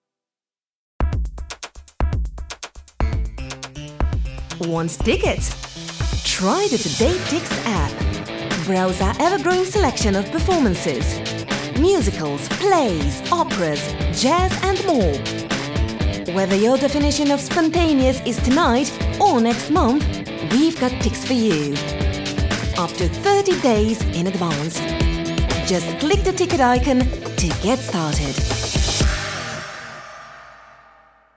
Female
Yng Adult (18-29), Adult (30-50)
Foreign Language
1227Commercial_British.mp3